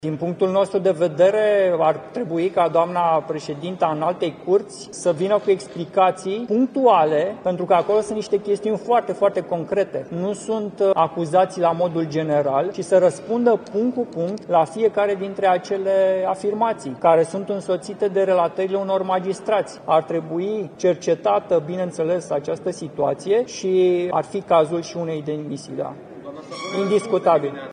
În cadrul unei conferințe de presă – în care USR l-a chemat pentru explicații în fața plenului Parlamentului pe actualul ministru al Justiției, Radu Marinescu -, deputatul a explicat că ar trebui să fie deschis un dosar de cercetare.